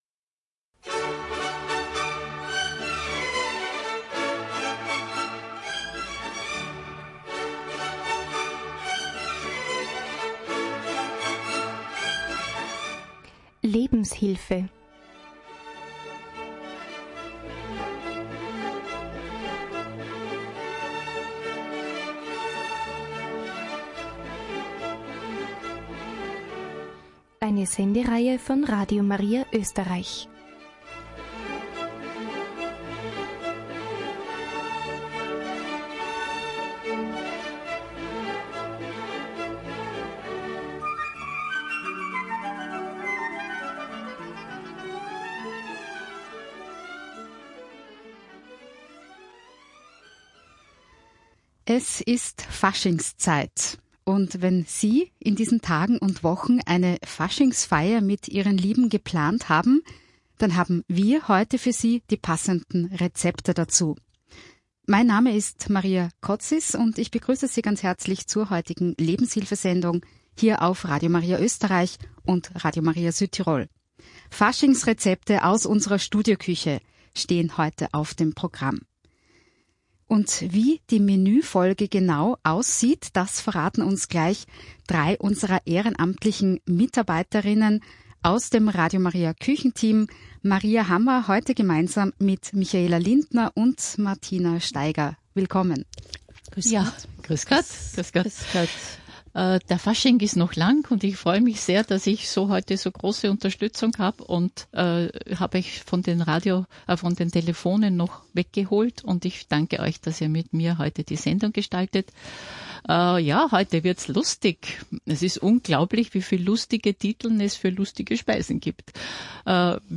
unsere ehrenamtlichen Köchinnen